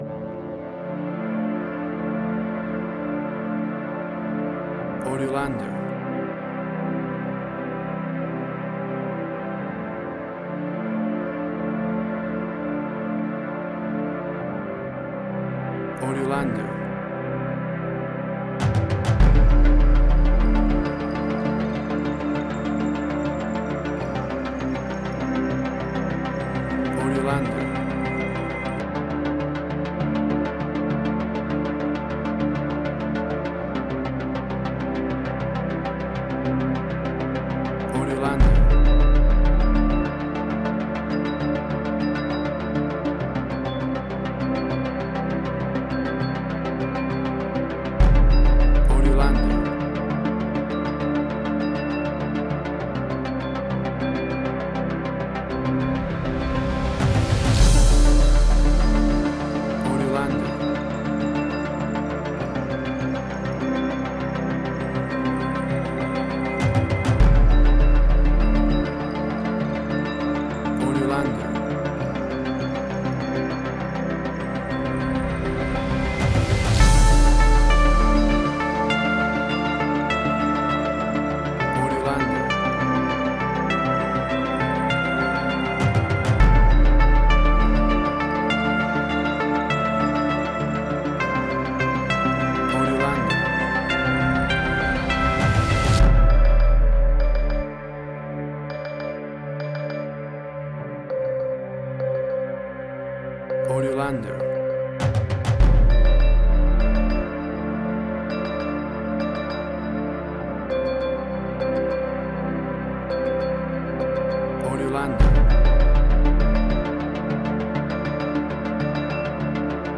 Big impact suspense reality TV style tension music.
Tempo (BPM): 101